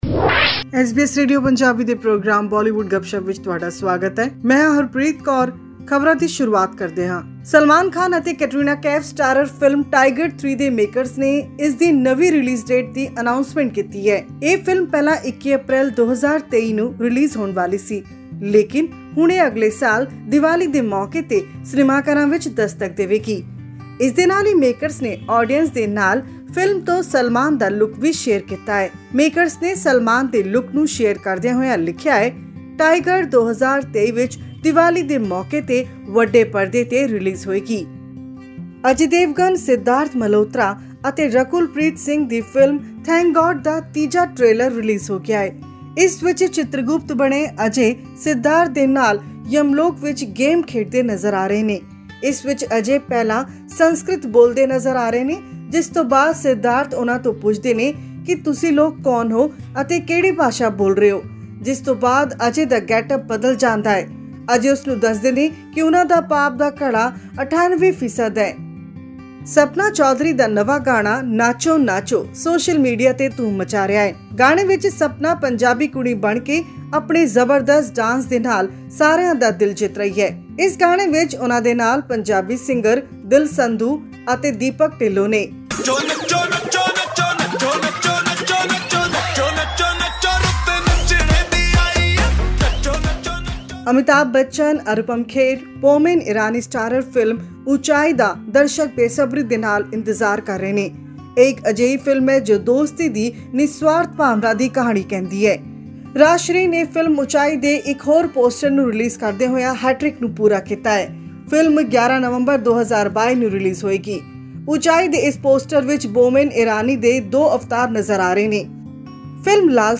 Although Amir Khan’s Laal Singh Chaddha failed to prove its worth at the box office, it has smashed the OTT platform by attaining the top tenth position in thirteen countries around the globe. This and much more in our weekly news bulletin from Bollywood.